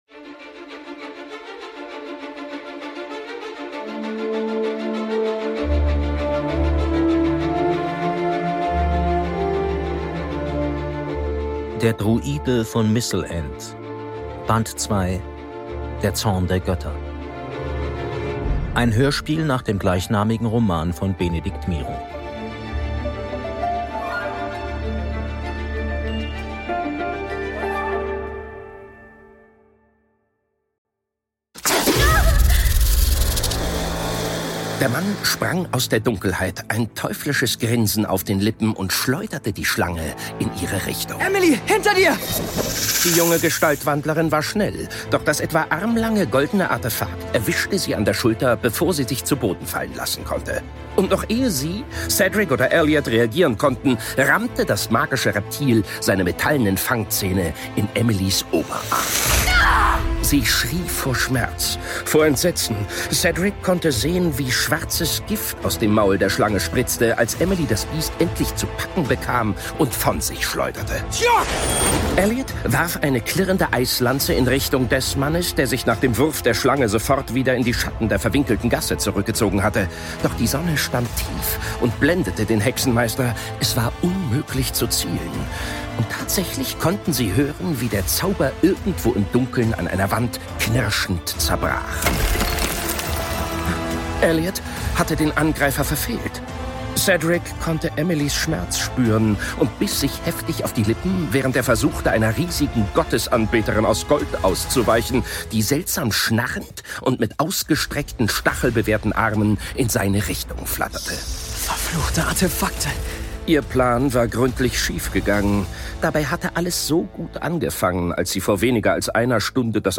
Hörspiel